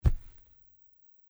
土路上的脚步声－偏低频－左声道－YS070525.mp3
通用动作/01人物/01移动状态/土路/土路上的脚步声－偏低频－左声道－YS070525.mp3